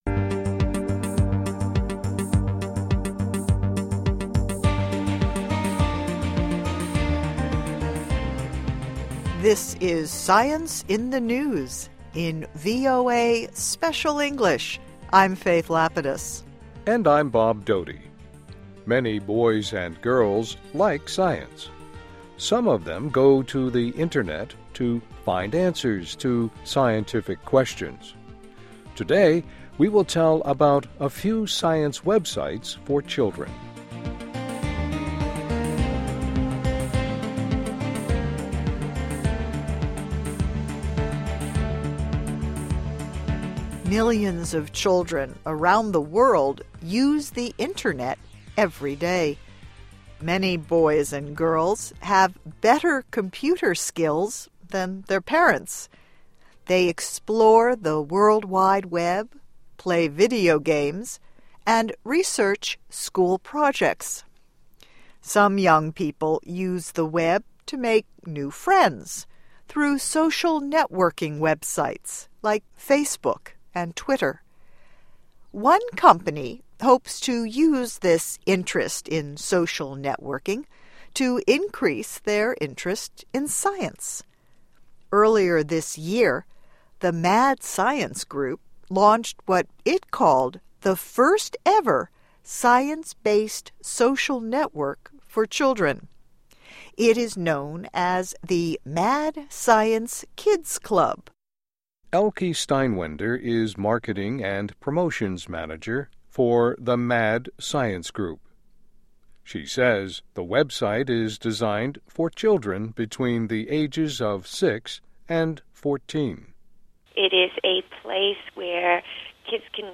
New Science Websites for Children (VOA Special English 2010-12-27)